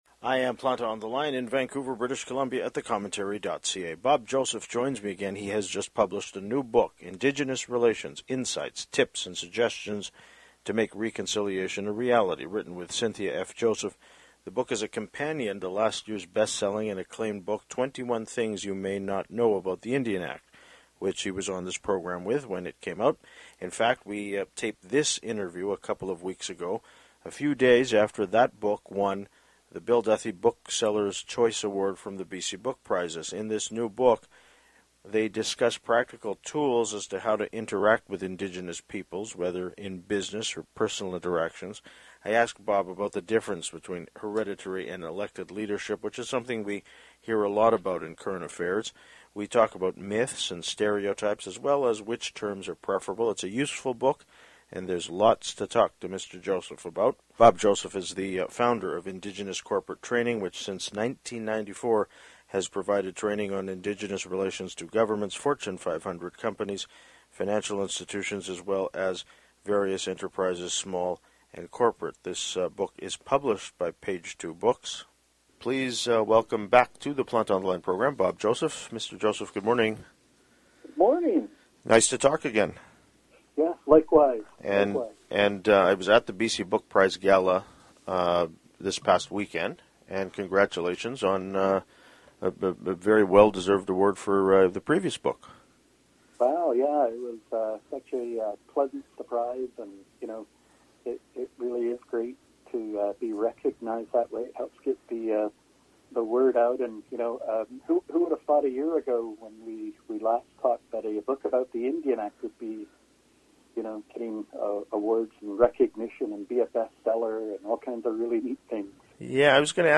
In fact, we taped this interview a couple of weeks ago, a few days after that book won the Bill Duthie Booksellers’ Choice Award from the BC Book Prizes.